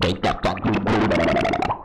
synthFX01.wav